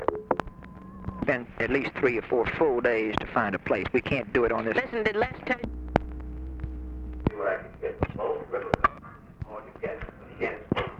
OFFICE CONVERSATION, December 10, 1963
Secret White House Tapes | Lyndon B. Johnson Presidency